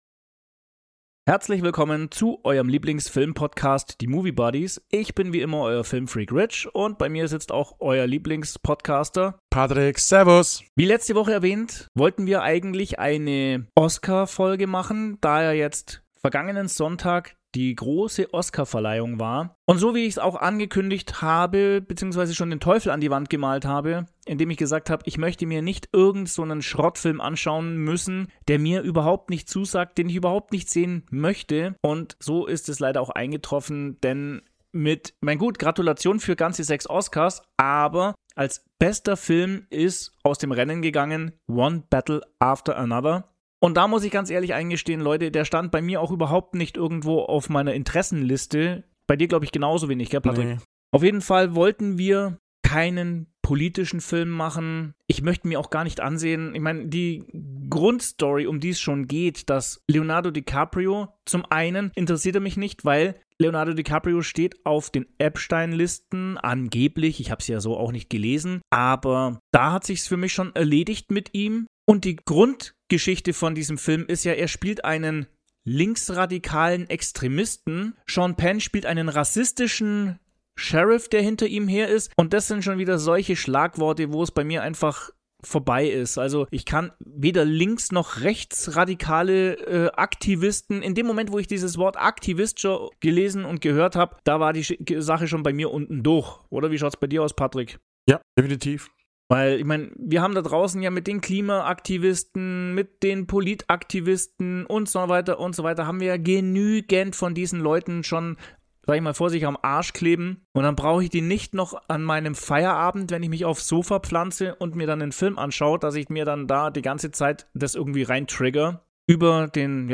Erlebt ein Gespräch zu einem der ganz grossen Filme der 1990er Jahre, der auch heute noch eine unbeschreiblich gute Unterhaltungsgranate ist. Hört die Meinungen zum Film, den Darstellern und der Aussage/Bedeutung des Filmes in Bezug auf die heutige Zeit.